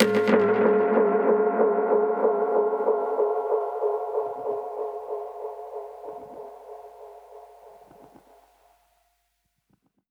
Index of /musicradar/dub-percussion-samples/95bpm
DPFX_PercHit_A_95-02.wav